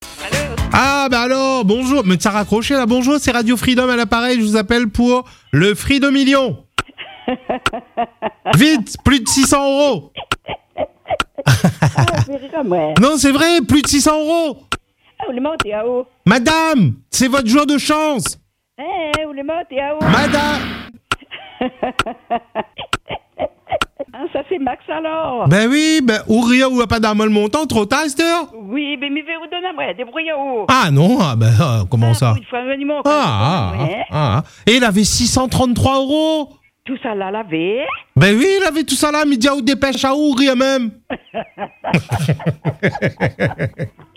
Bien au contraire : l’annonce s’est transformée en un véritable moment de rigolade, ponctué d’un fou rire communicatif.